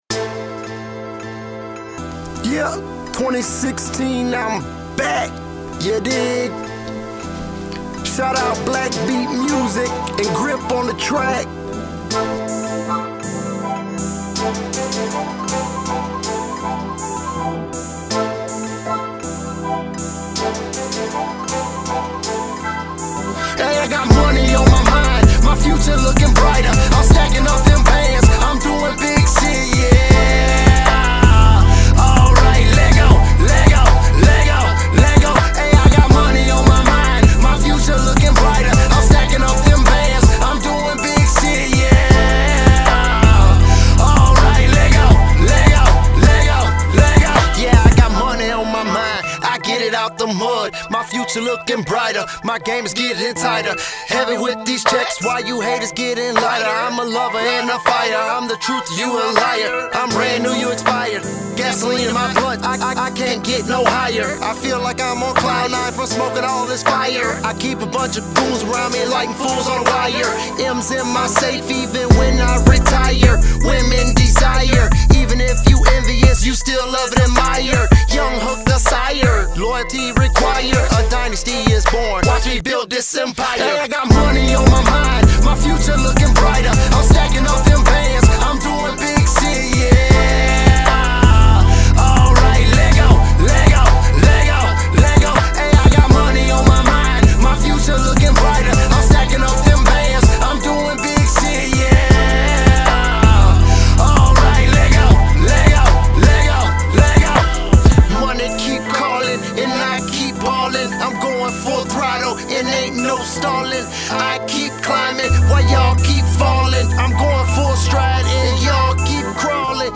Trap
Description : Trap music